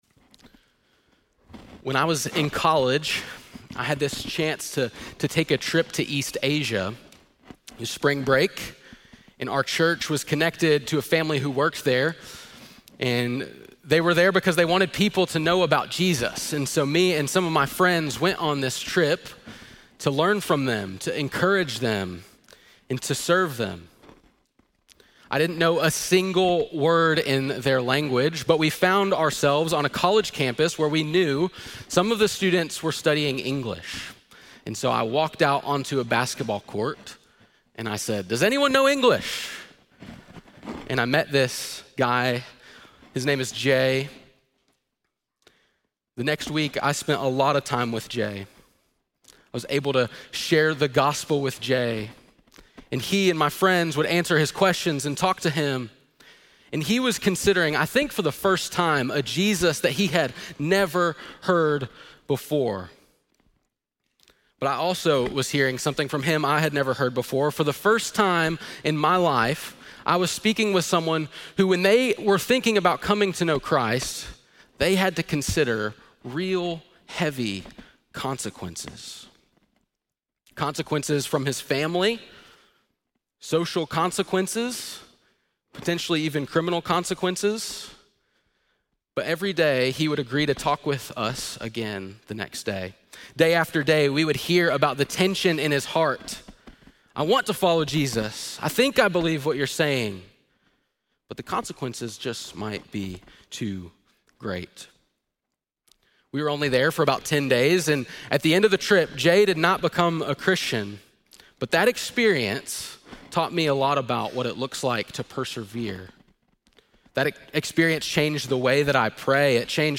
4.12-sermon.mp3